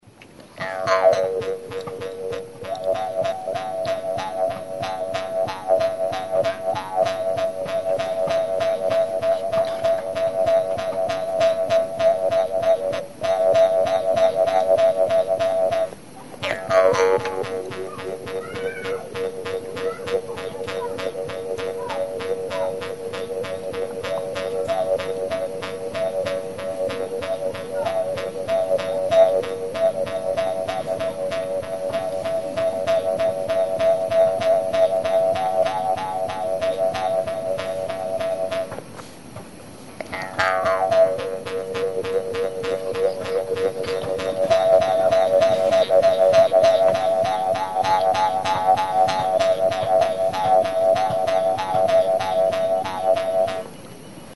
Music instrumentsSUSAP; Jew's harp
Idiophones -> Plucked / flexible -> Without sound board
Musik aus dem Bergland West-Neuguineas.
Banbuzko tablatxo borobildua da.
Puntako bi aldeak loturik daude eta atzeko muturrean duen sokatxoarekin astintzen da mihi hori; horrela honek bibratu eta hotsa lortzen da.
CANE; BAMBOO